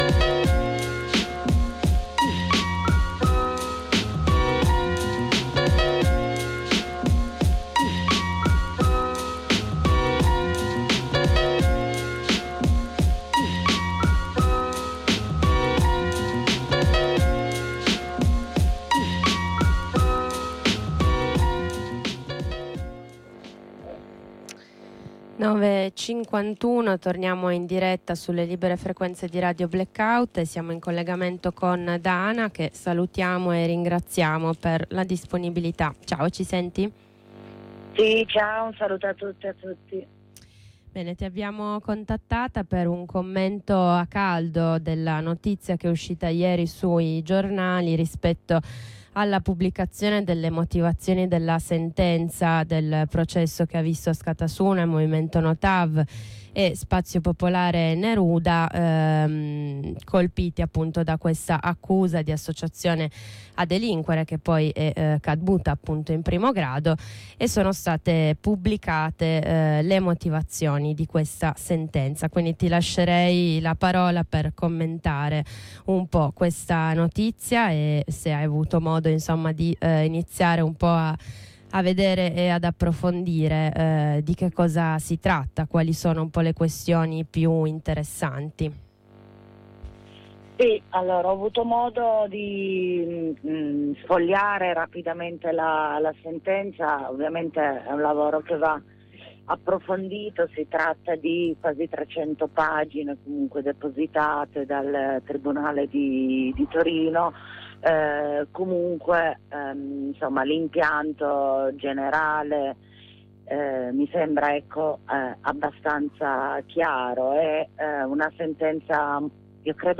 Un commento a caldo